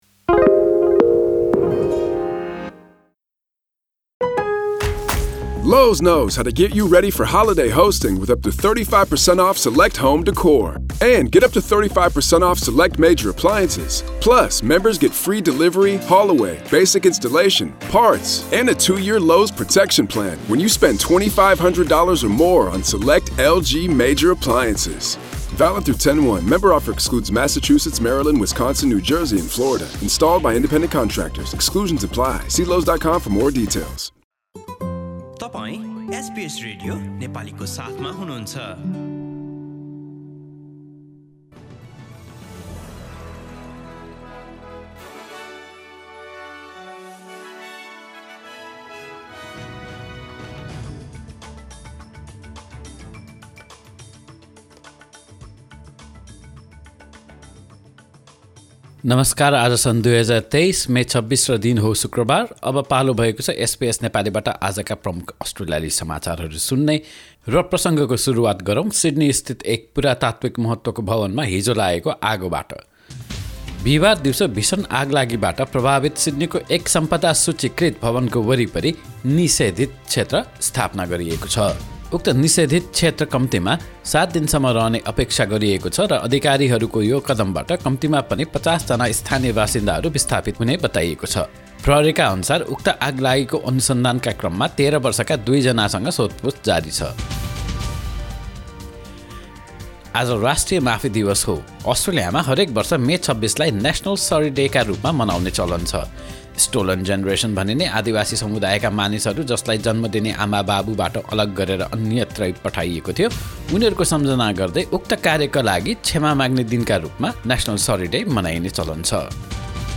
एसबीएस नेपाली प्रमुख अस्ट्रेलियाली समाचार: शुक्रवार, २६ मे २०२३